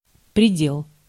Ääntäminen
IPA: /prʲɪˈdʲel/